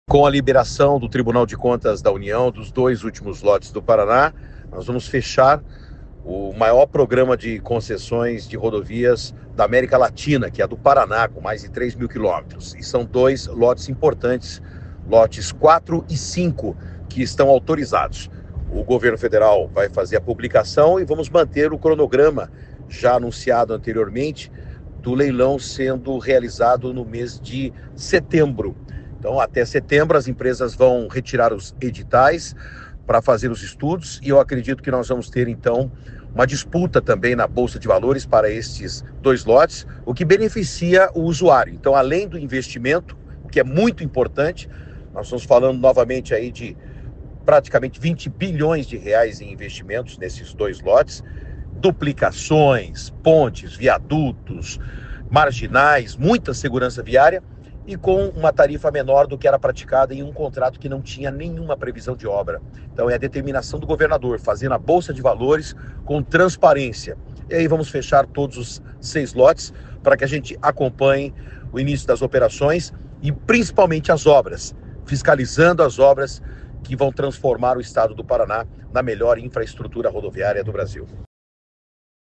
Sonora do secretário de Infraestrutura e Logística, Sandro Alex, sobre a aprovação dos editais dos lotes 4 e 5 das rodovias do Paraná